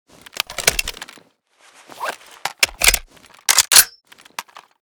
fnc_reload_empty.ogg